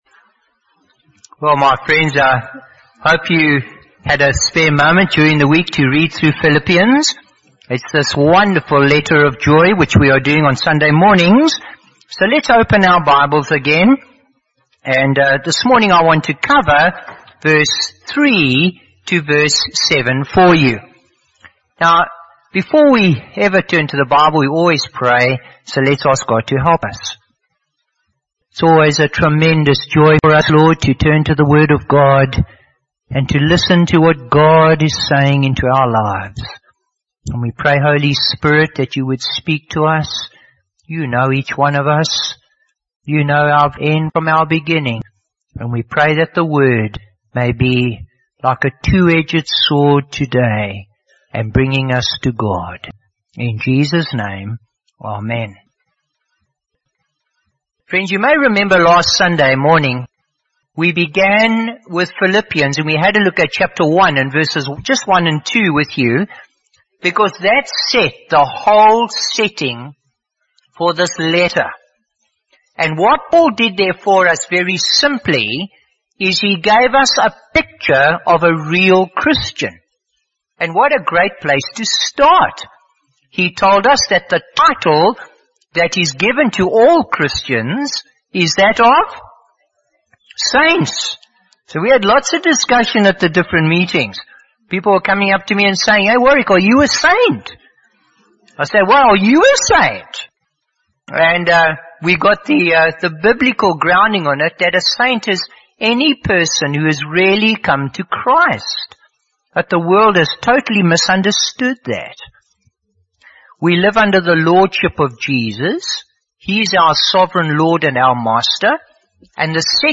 Bible Text: Philippians 1:3-7 | Preacher: Bishop Warwick Cole-Edwards | Series: Philippians